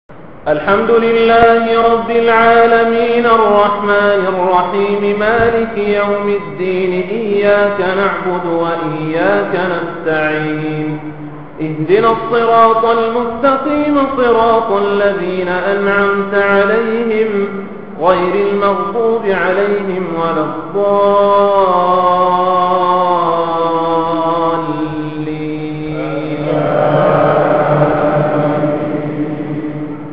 تلاوات قرآنية